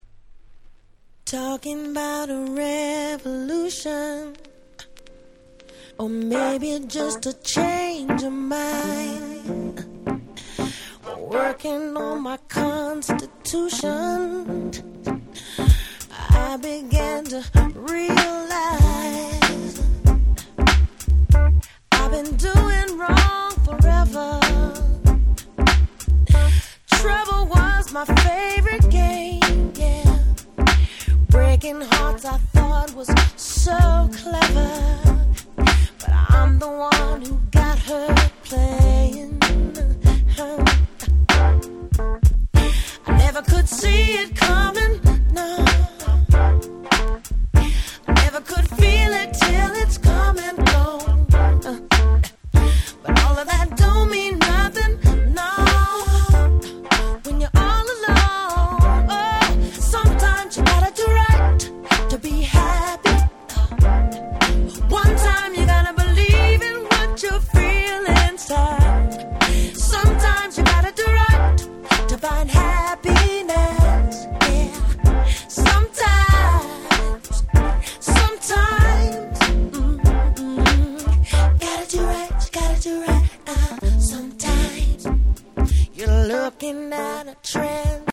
97' Very Nice Acid Jazz !!